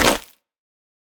Minecraft Version Minecraft Version 1.21.5 Latest Release | Latest Snapshot 1.21.5 / assets / minecraft / sounds / block / mangrove_roots / break6.ogg Compare With Compare With Latest Release | Latest Snapshot